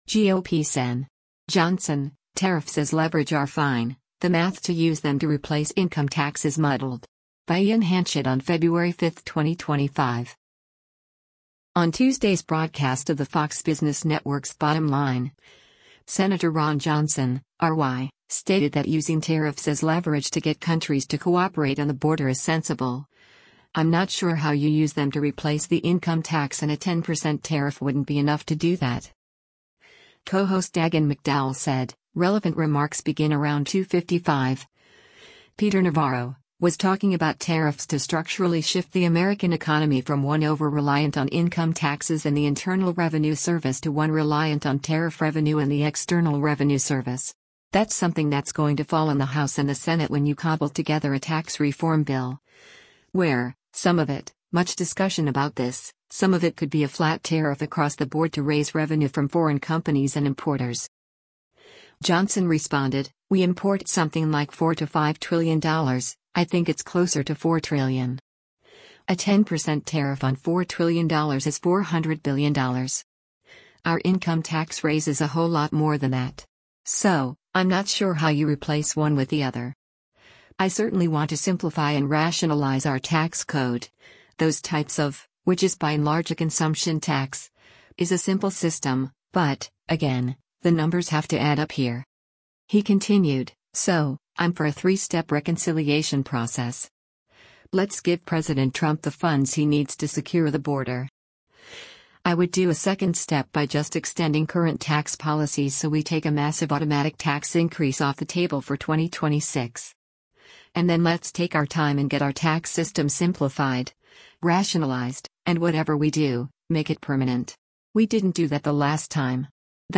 On Tuesday’s broadcast of the Fox Business Network’s “Bottom Line,” Sen. Ron Johnson (R-WI) stated that using tariffs as leverage to get countries to cooperate on the border is sensible, “I’m not sure how” you use them to replace the income tax and a 10% tariff wouldn’t be enough to do that.